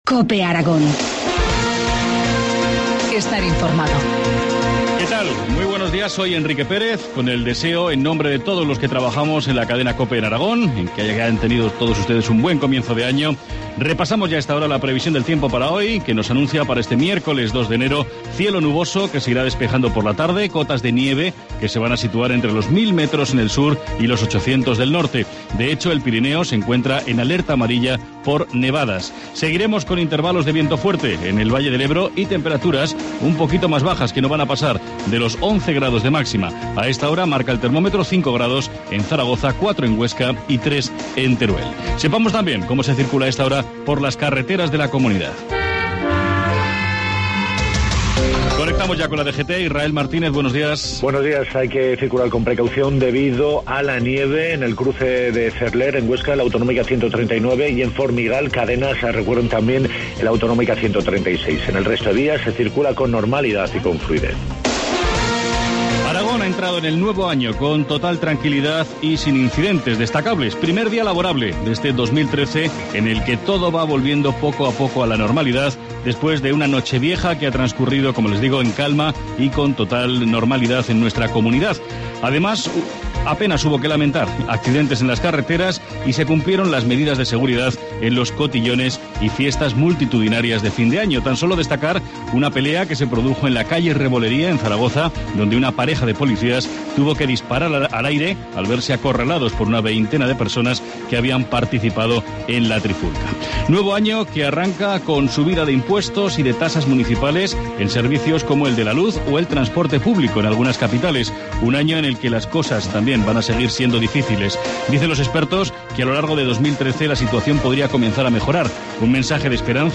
Informativo matinal, miércoles 2 enero, 7,25 horas